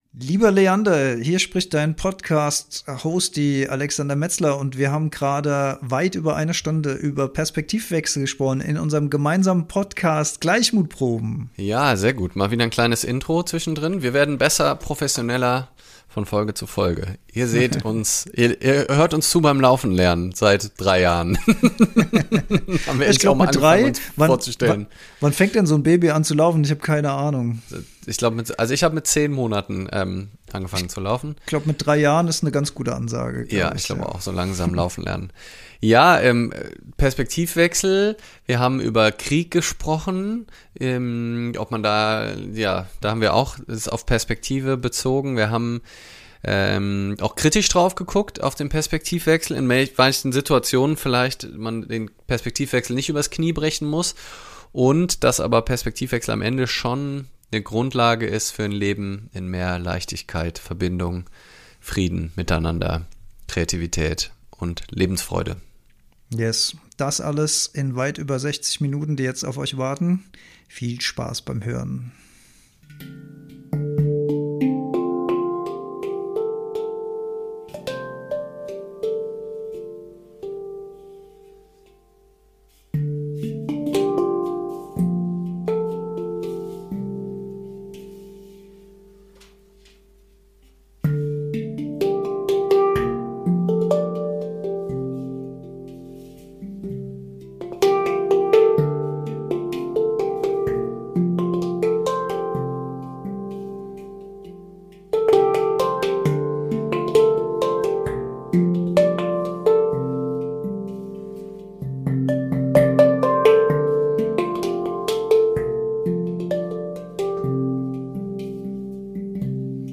Insta live vom 25.12.24